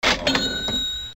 notif-hotmart.mp3